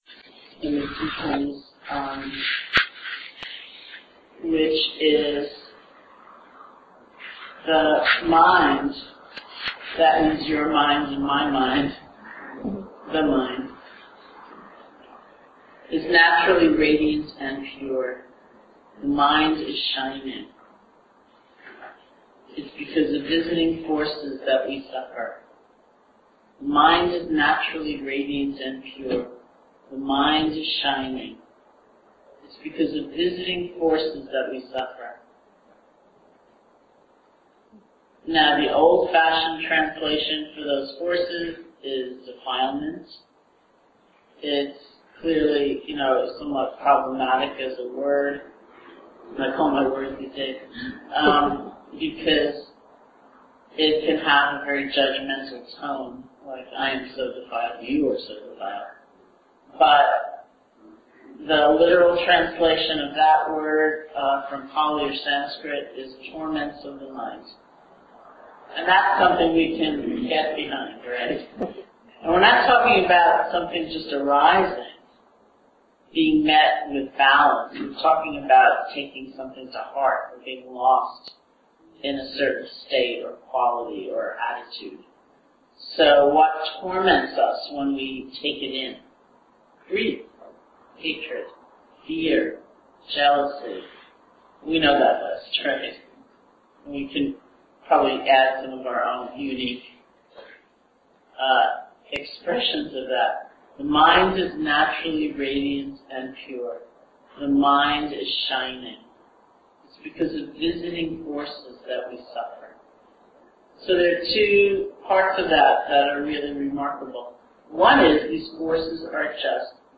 Teacher: Sharon Salzberg Date: 2012-07-21 Venue: Seattle Insight Meditation Center Series [display-posts] Description Lovingkindness is a meditation that cultivates our natural capacity for an open and loving heart.